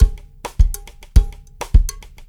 CWS DRUMS+-R.wav